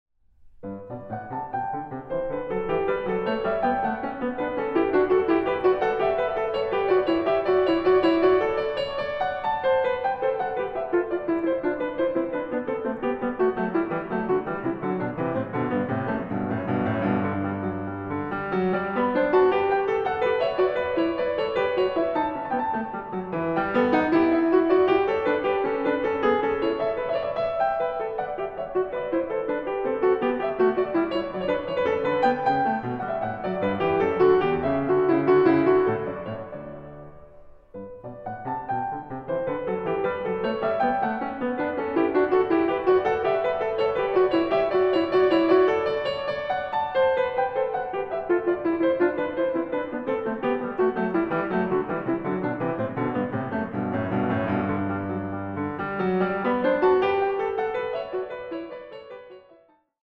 a 2 Clav.